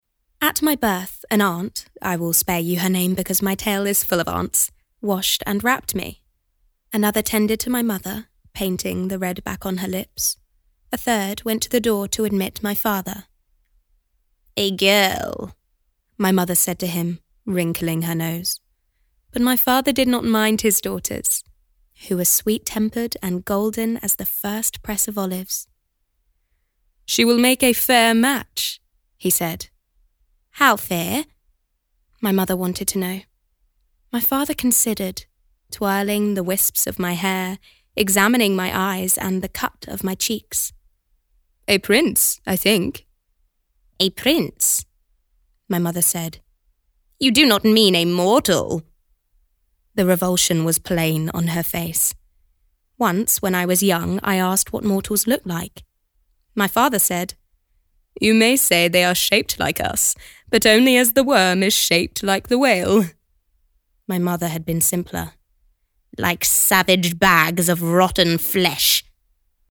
Audiobook Showreel
a warm, playful and clear-toned voice, with an abundance of accents and characters to play with!
Female
Neutral British